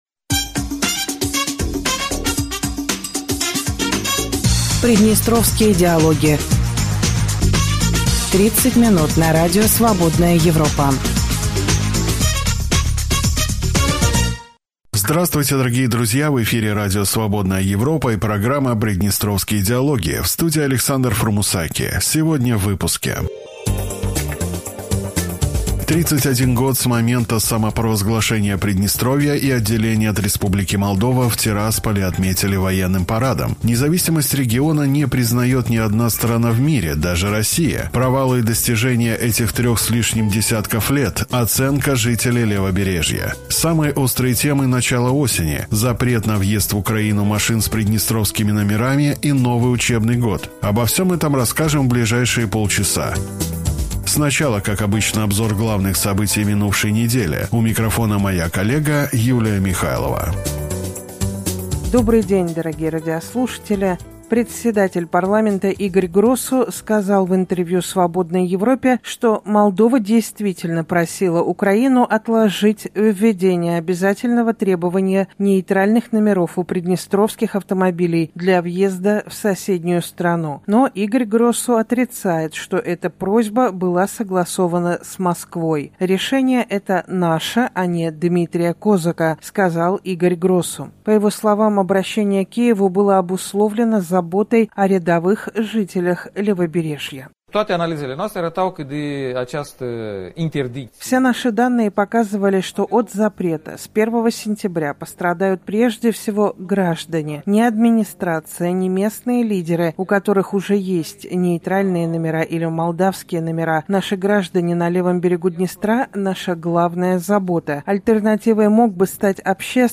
В эфире Радио Свободная Европа и программа Приднестровские диалоги.